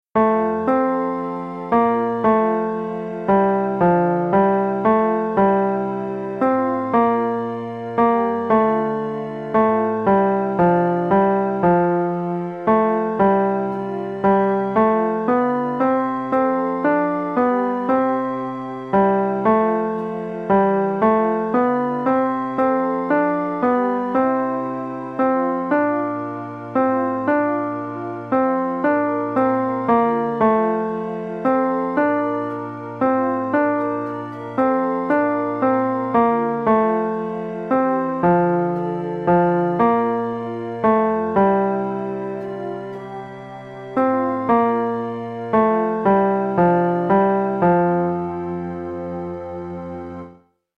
Chorproben MIDI-Files 469 midi files